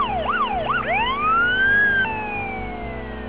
zrtpAlert.wav